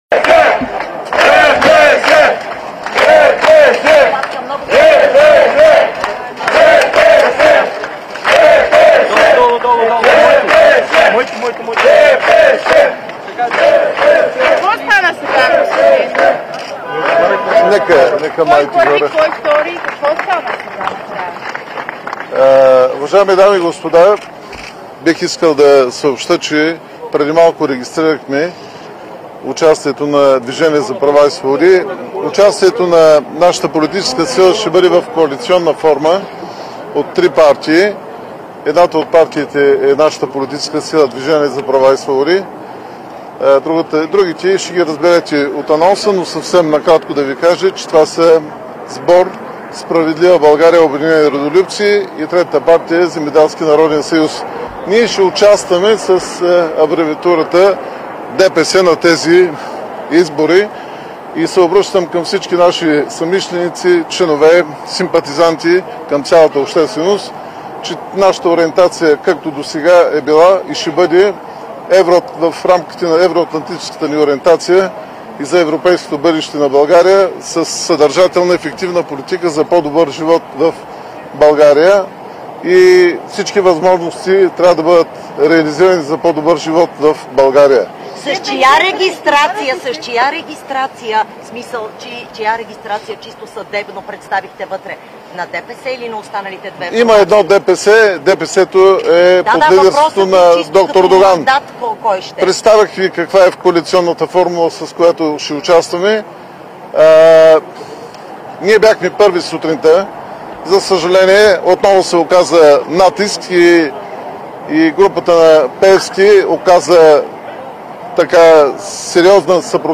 15.45 - Брифинг на Искра Михайлова и Байрам Байрам след регистрацията на ДПС - Ново начало за участие в изборите.  - директно от мястото на събитието (пл. „Княз Александър I" №1)
Директно от мястото на събитието